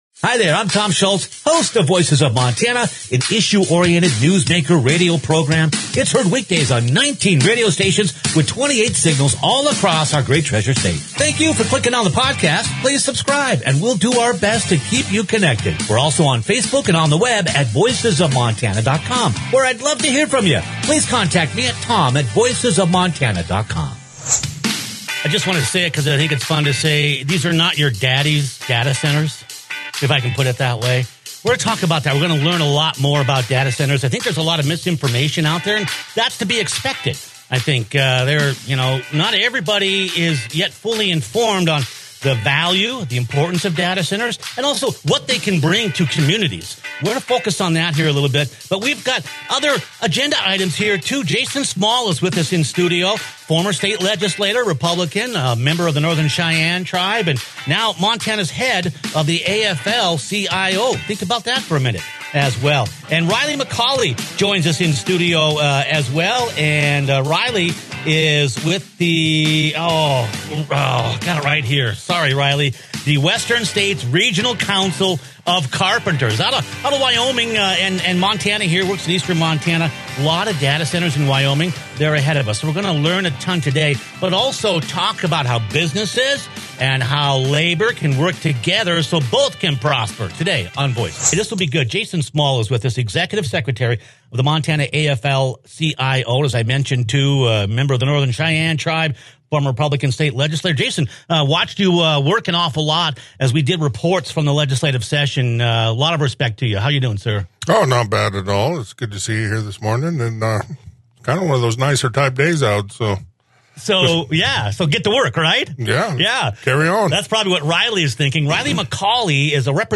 Click on the podcast for an interesting look at an evolving economic and political landscape, as two union leaders discuss their support for new tech development projects that environmental organizations often oppose.